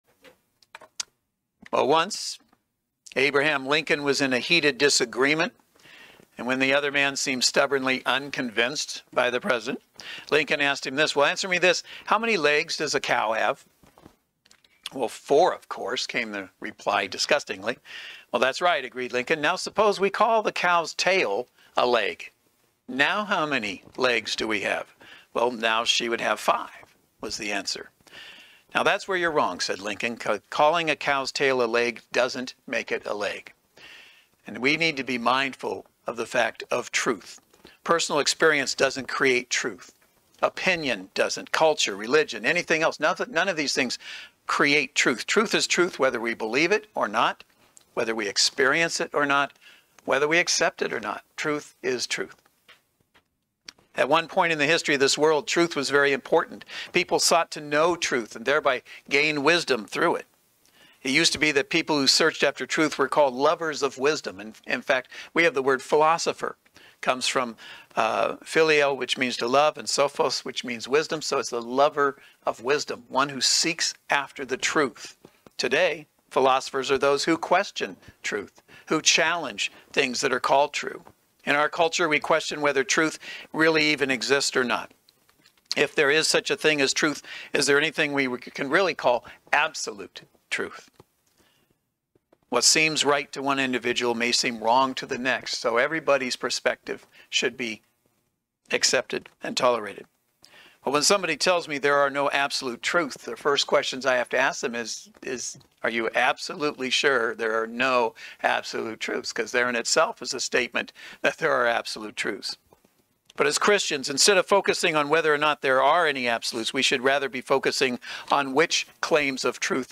Service Type: Saturday Worship Service The greatest danger to Christians today comes not from outside the Church – but from within.